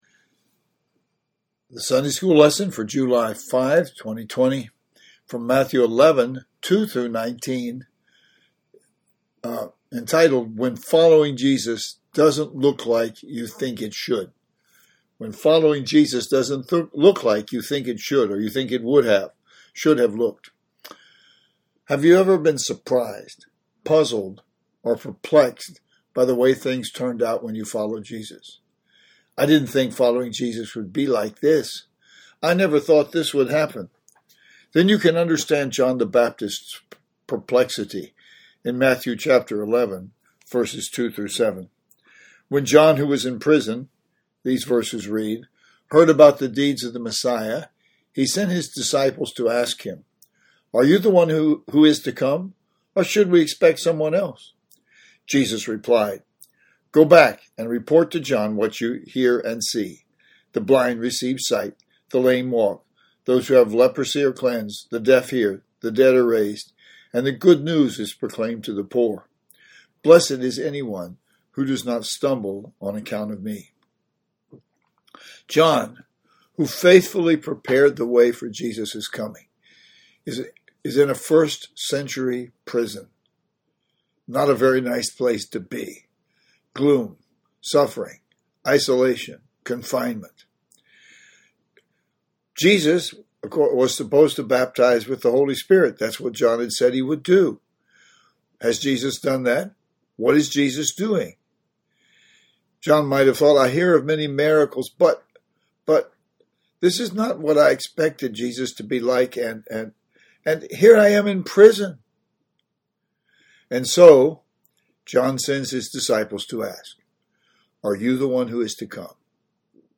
How do we react when following Jesus doesn’t look like we think it should? Jesus addresses that question in today’s lesson from Matthew 11:2-19. Click below for an audio exposition of this passage.